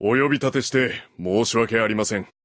Menu Voice Lines